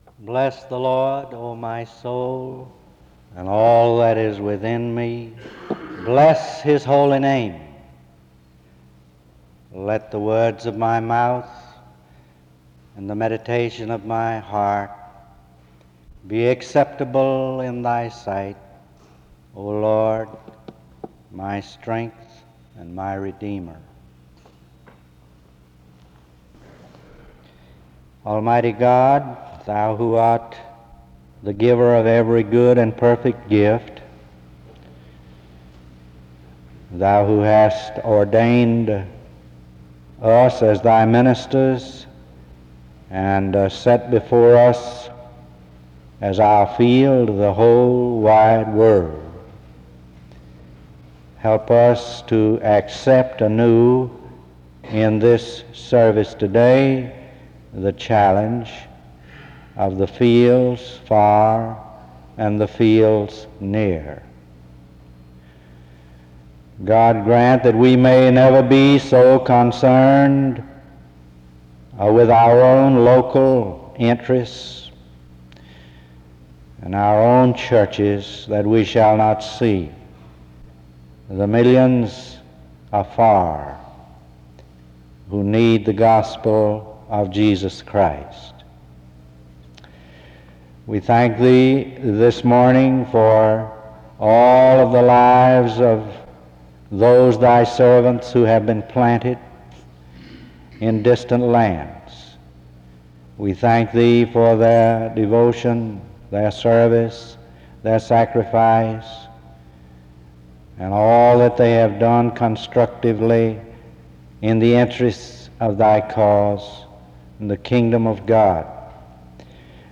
The service opens with a word of prayer from 0:00-3:38. There are announcements and an introduction to the speaker from 3:43-7:33.